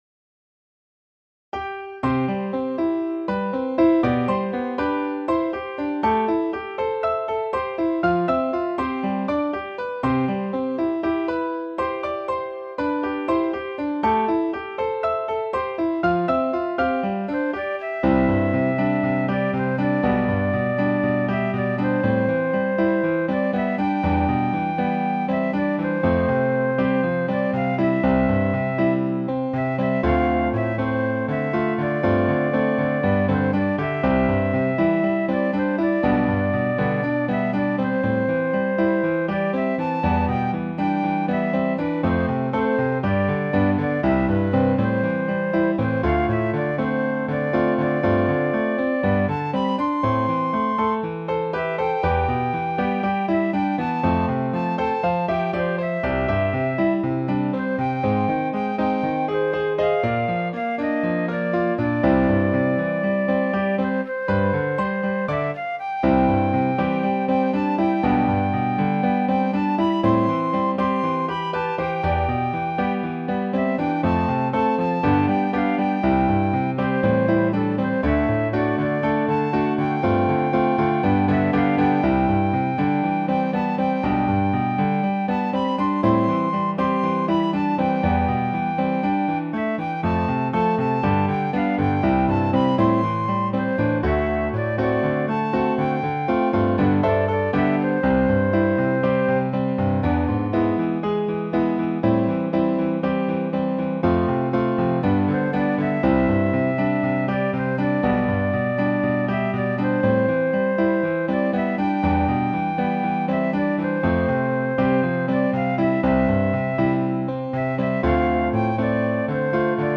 校歌の作詞・作曲は、光市とのつながりのあるシンガーソングライターの川嶋あいさんに依頼をさせていただき、皆様からお寄せいただいた言葉・フレーズの意味や背景、大和地域へのご理解を深めていただきながら制作が進められました。
♪光る明日へ（メロディー）(音声ファイル:4.1MB)